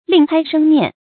另开生面 lìng kāi shēng miàn
另开生面发音